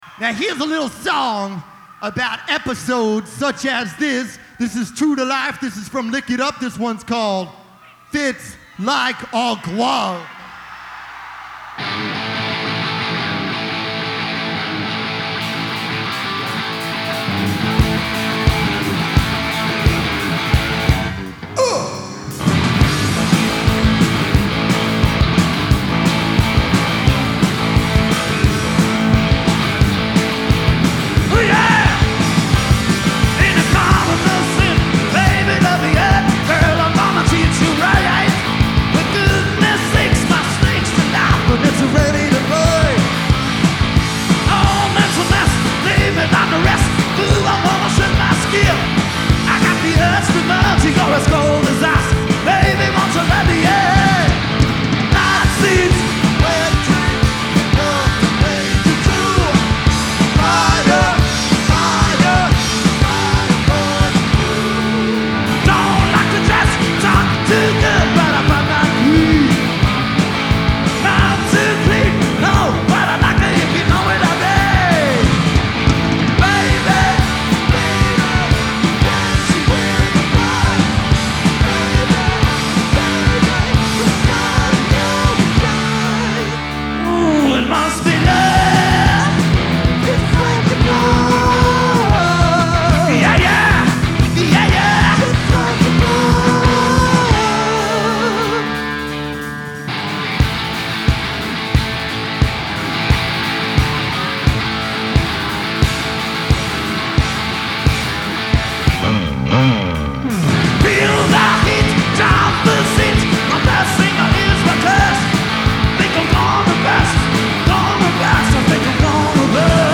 Genre : Rock
Live From Mid-Hudson Civic Arena, Poughkeepsie NY